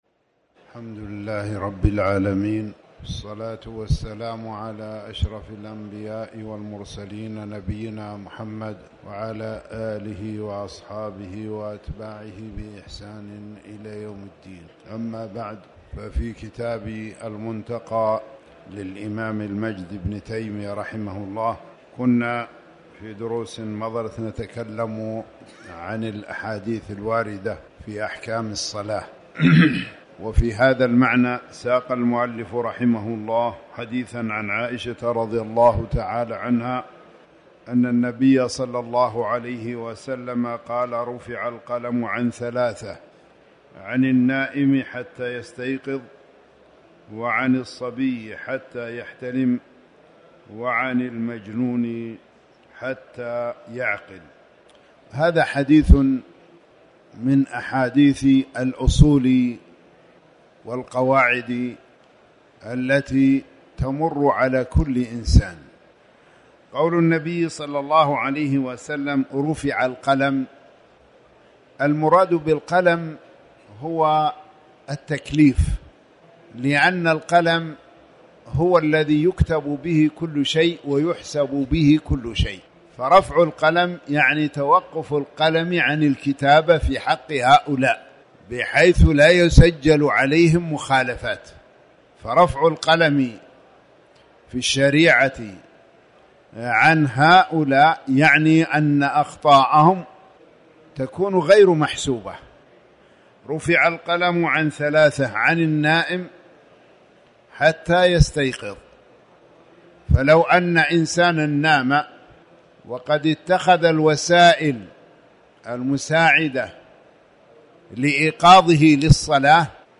تاريخ النشر ٦ رمضان ١٤٣٩ هـ المكان: المسجد الحرام الشيخ